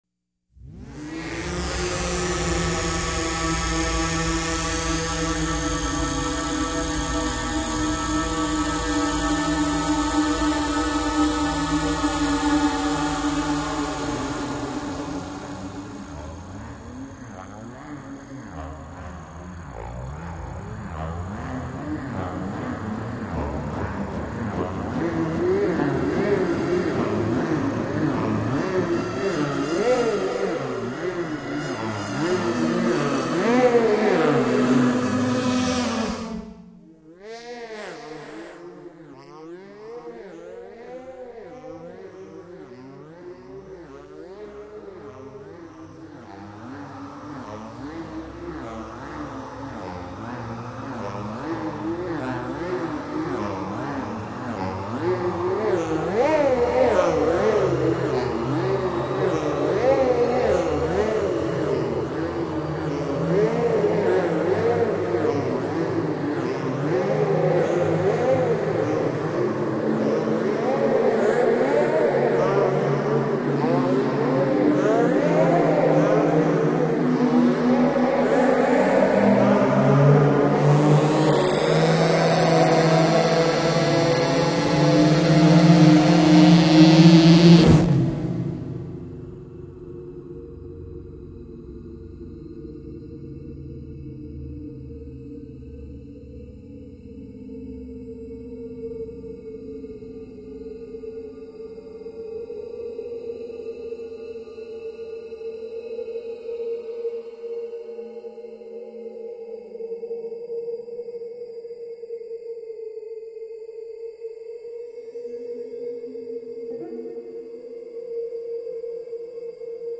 Wavemaker 4 + effects.